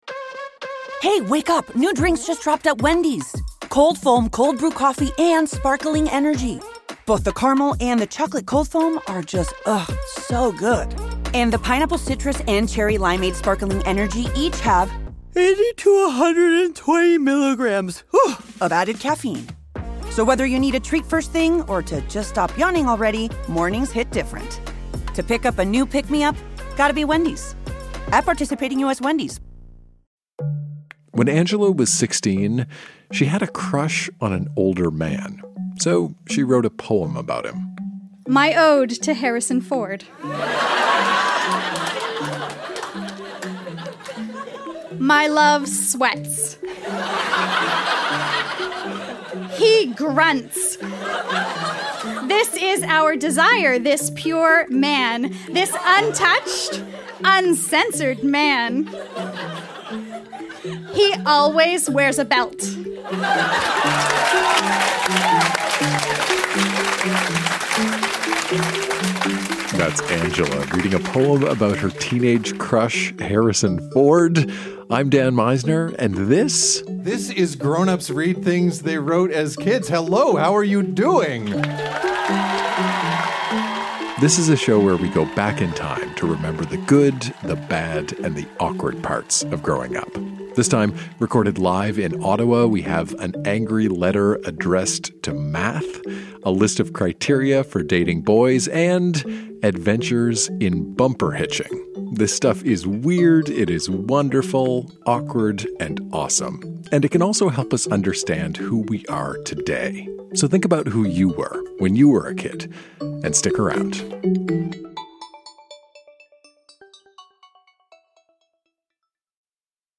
Recorded live in Ottawa, ON.